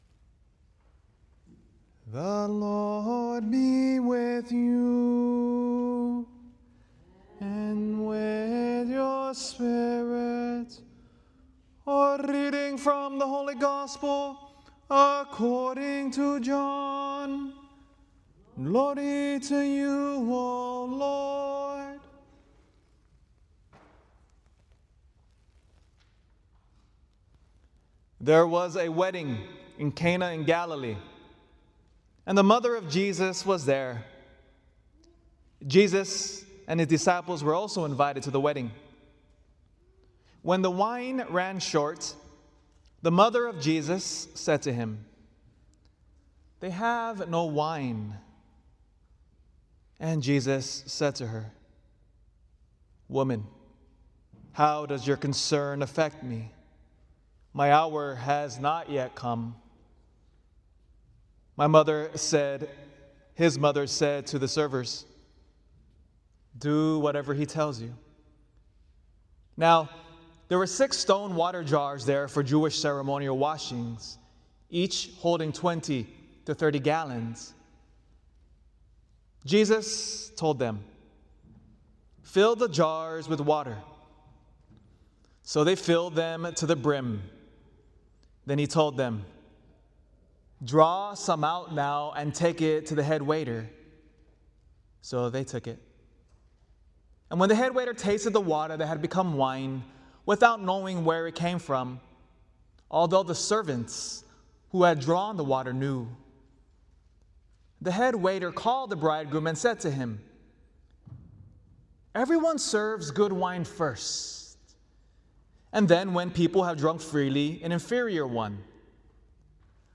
The following homily was delivered at a recent community wedding in which five couples, originally civilly married, had their marriages convalidated in the Catholic Church.